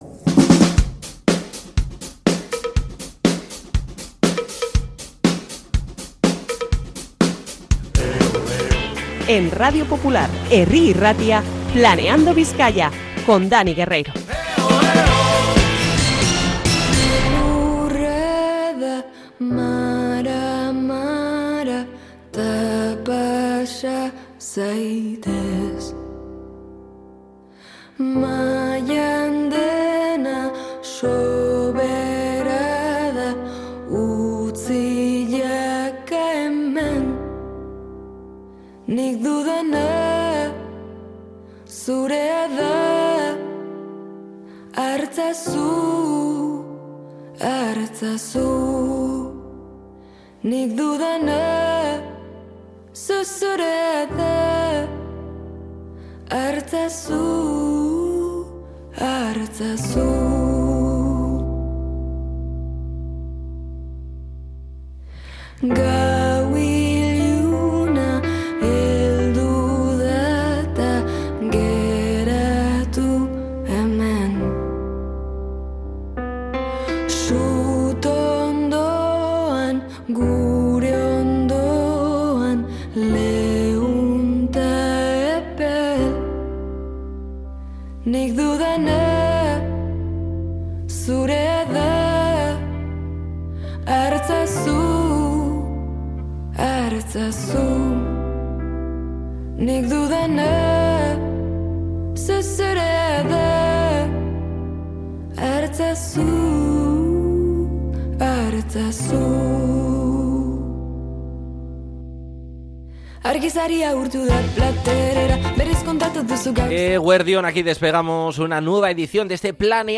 Entrevista con Jon Plazaola y más propuestas para disfrutar del finde en Bilbao, Bizkaia y alrededores